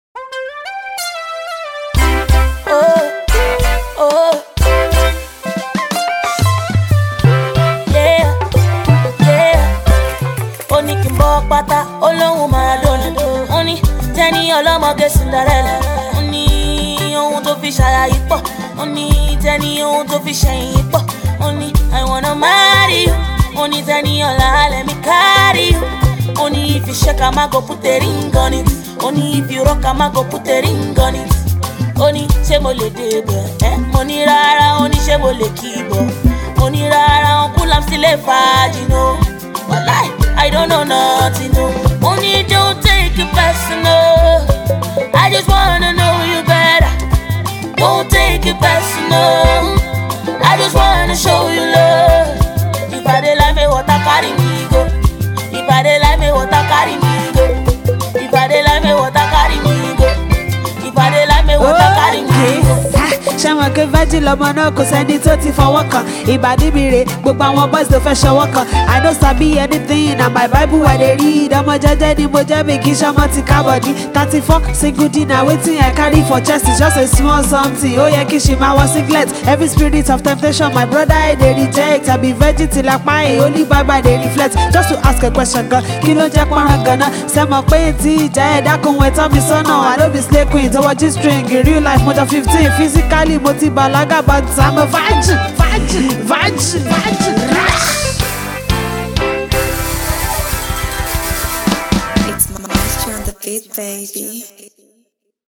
female rapper